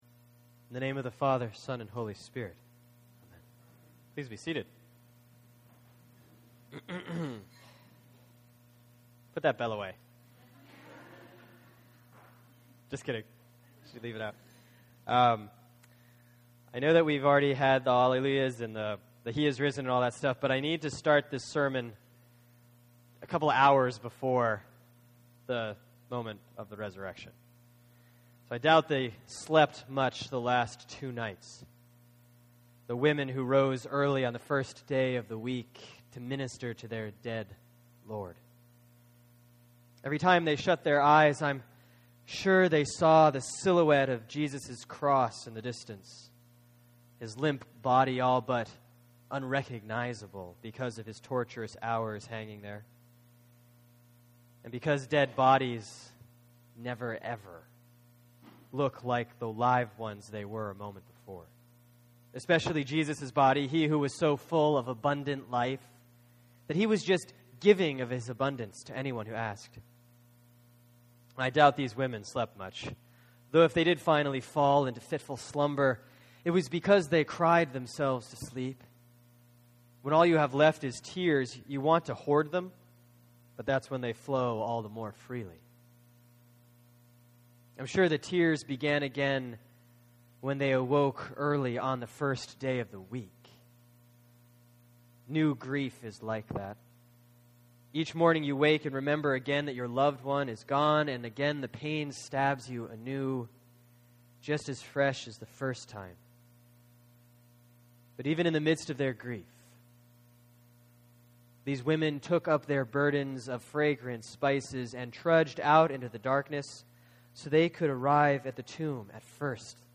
(Sermon for Saturday, March 30, 2013 || The Easter Vigil || Year B || Luke 24:1-12)